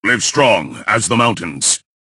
bo_start_vo_01.ogg